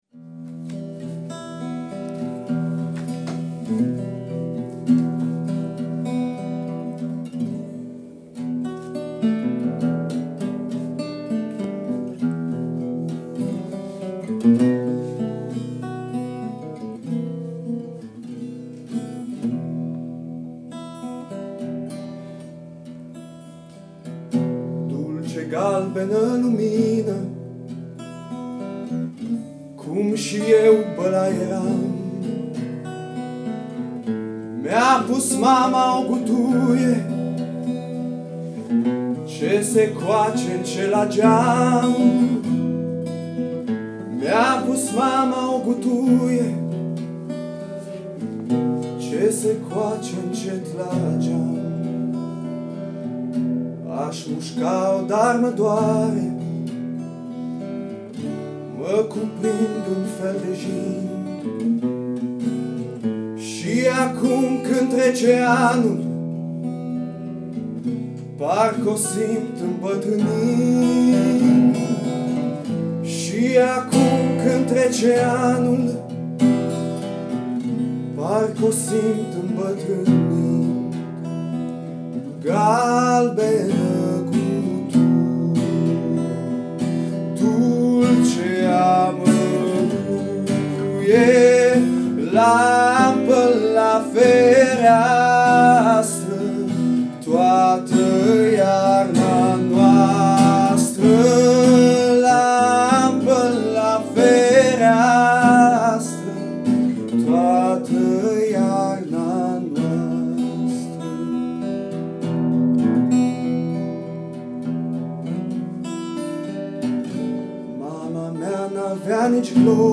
A_Colind-1-Dulce.-4-minute.mp3